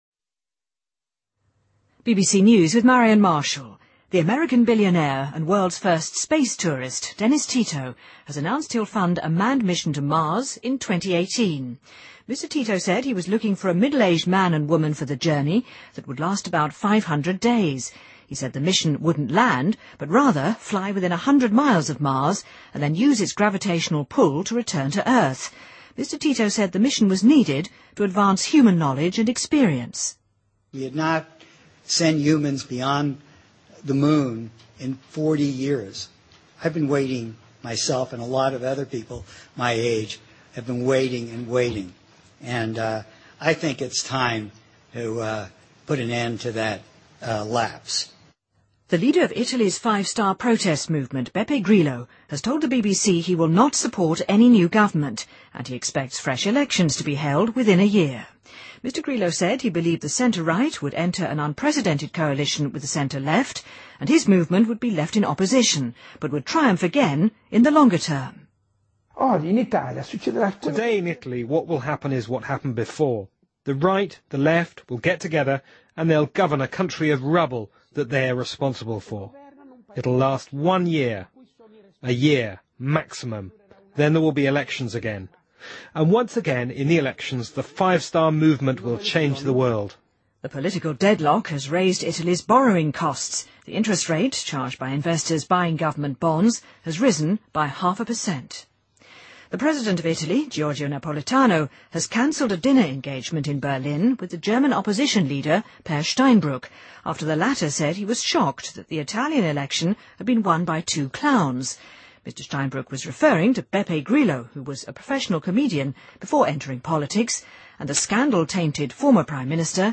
BBC news,奥巴马敦促国会避免实施开支削减方案